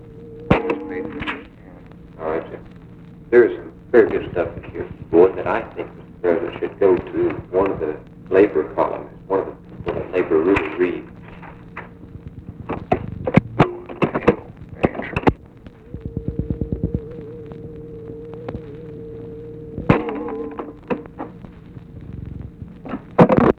OFFICE CONVERSATION, January 7, 1964
Secret White House Tapes | Lyndon B. Johnson Presidency